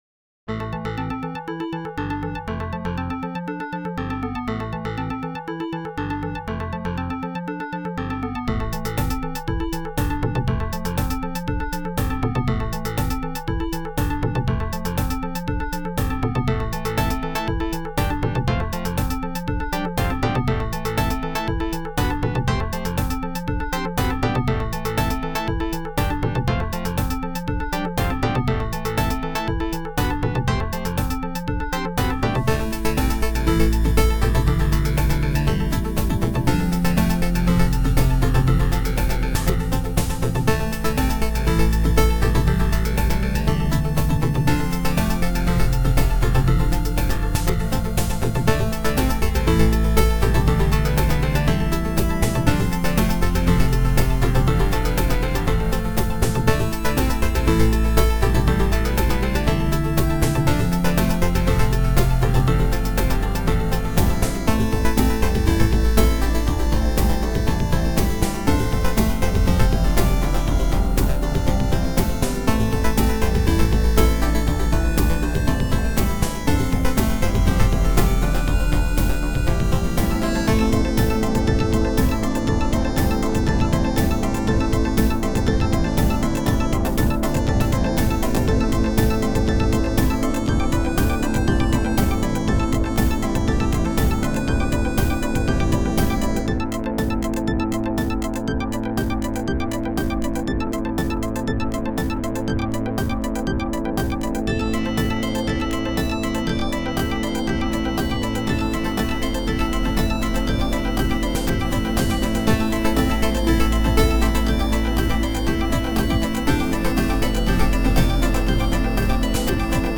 A new chiptune mix of 4 tracks